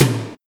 TOM07.wav